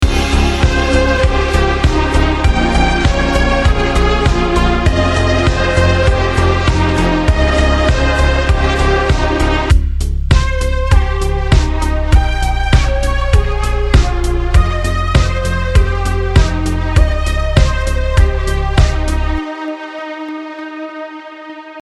Слышу аккуратный дисторшн, атака не сразу, много дилея.